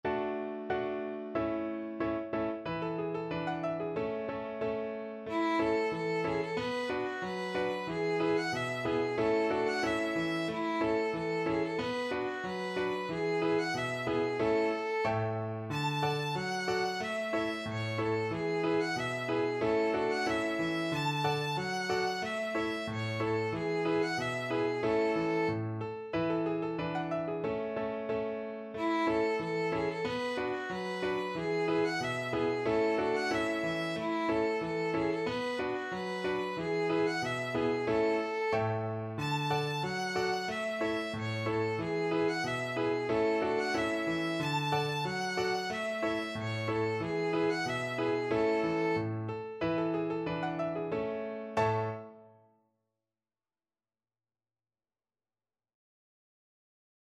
Violin
A major (Sounding Pitch) (View more A major Music for Violin )
Jolly =c.92
2/2 (View more 2/2 Music)
Classical (View more Classical Violin Music)
Swiss